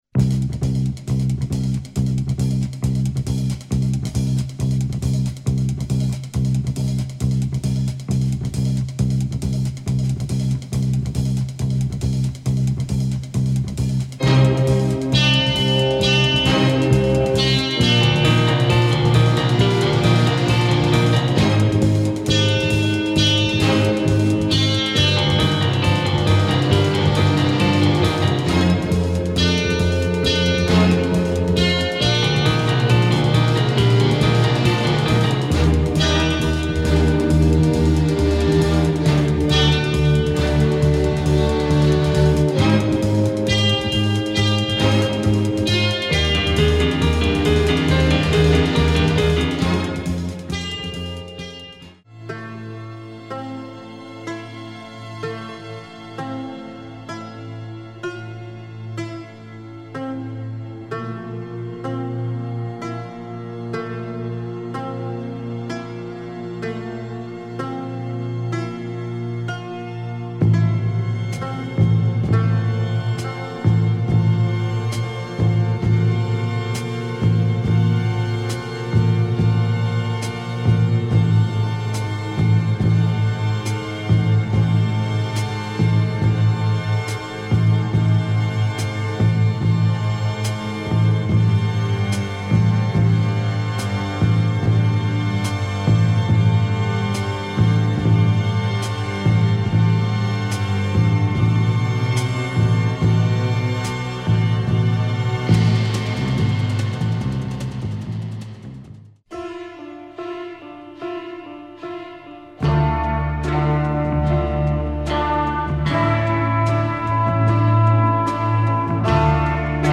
soundtrack
Deep cinematic, Italian groove.